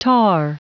Prononciation audio / Fichier audio de TAR en anglais
Prononciation du mot tar en anglais (fichier audio)